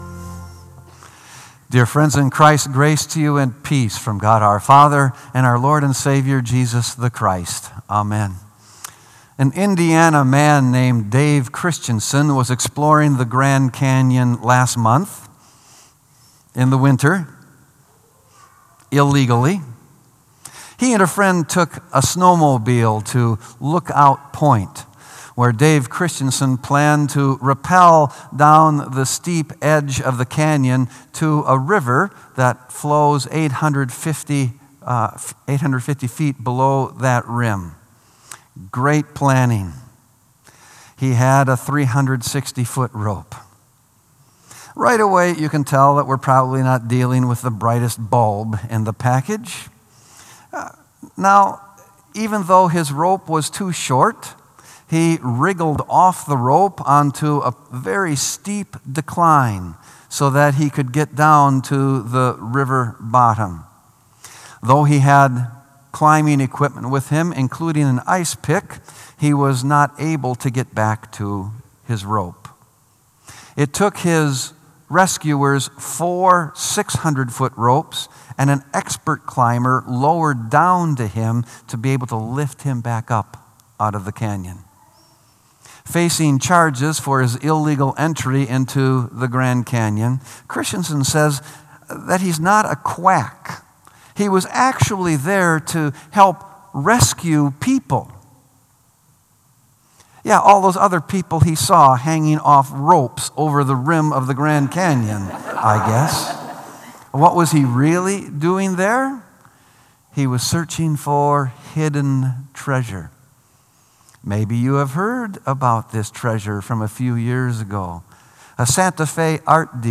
Sermon “Treasure Hunt”